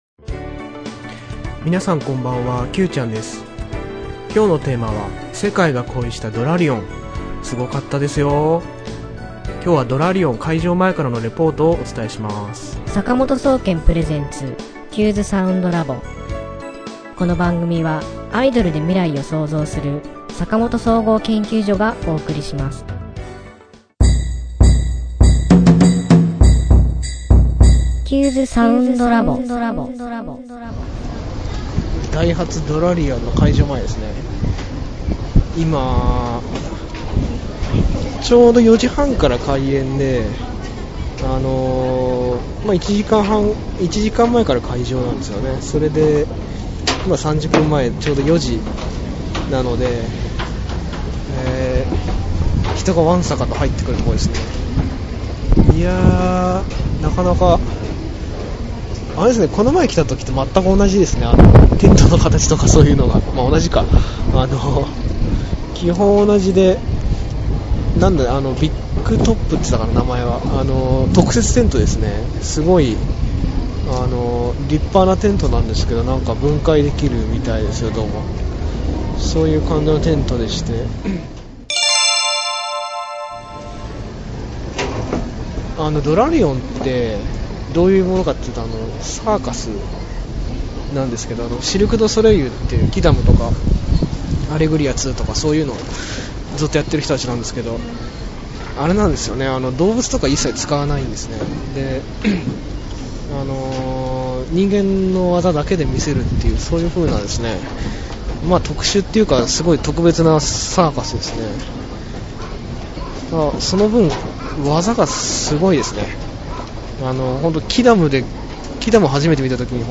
興奮のパフォーマンスレポートをポッドキャスティング（音声放送）でお伝えします。＜イベントの制約につき、会場内での音声は収録されていませんのであらかじめご了承ください。＞